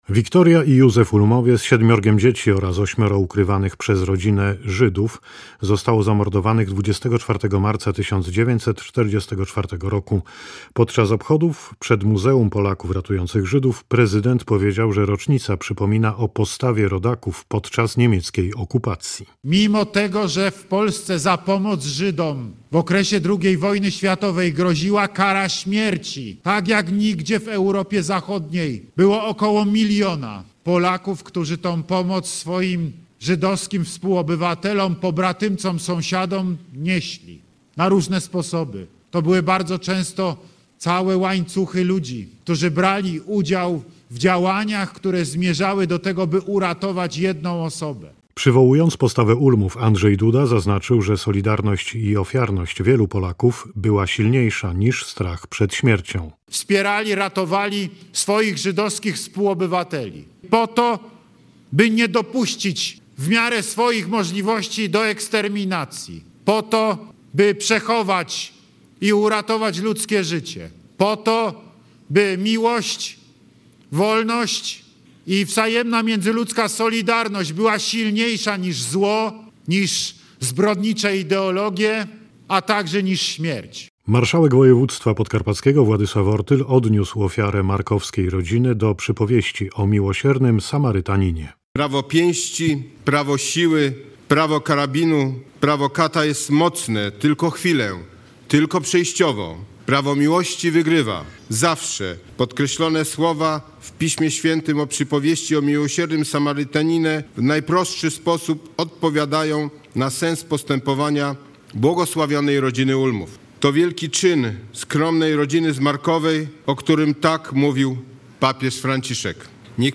W Narodowym Dniu Pamięci o Polakach Ratujących Żydów podczas okupacji niemieckiej – w Markowej – odbyły się uroczystości z udziałem prezydenta Andrzeja Dudy. 24 marca przypada 80-ta rocznica zamordowania w tej miejscowości rodziny Ulmów wraz z ukrywanymi przez nich Żydami.